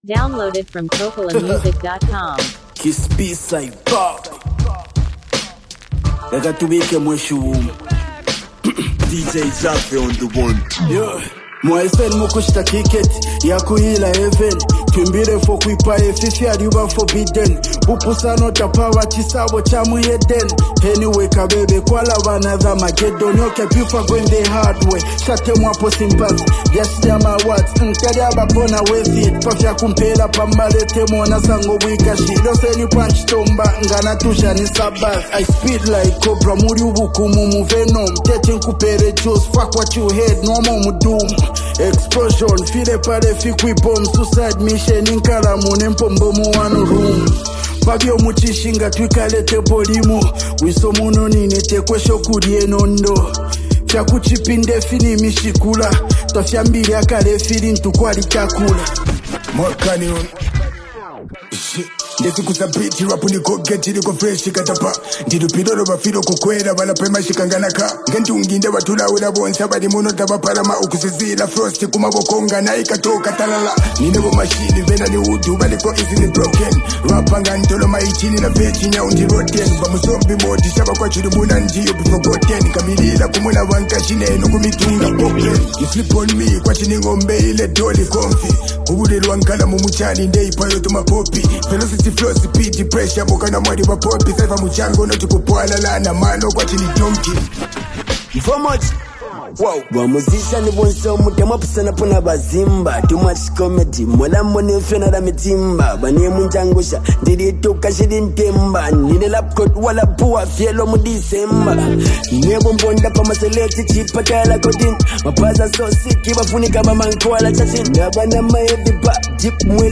hip-hop culture
trademark sharp wordplay and seasoned delivery